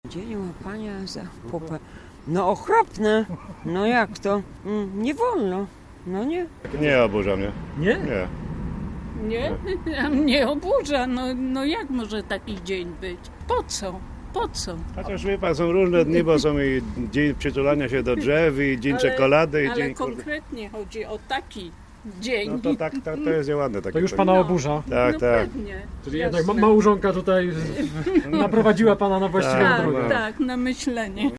Młodsi mówili, że im to nie przeszkadza. Starsi byli już zniesmaczeni i oburzeni.